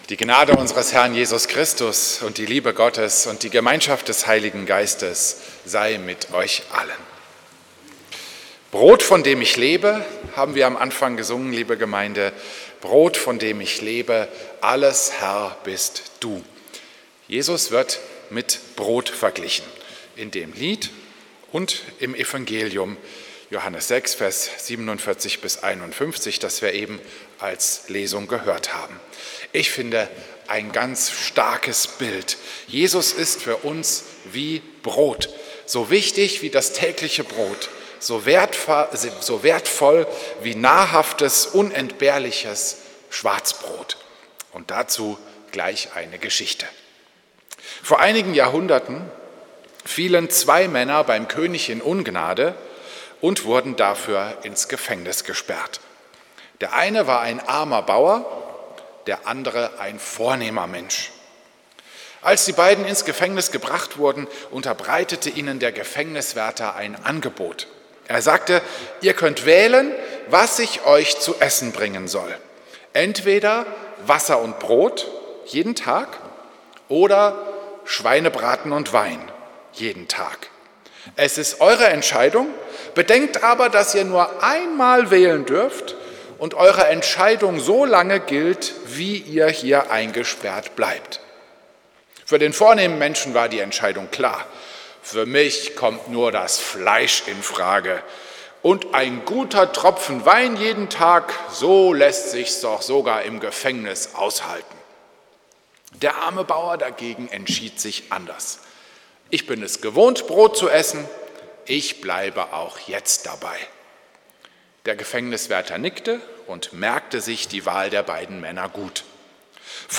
Predigt am Sonntag Laetare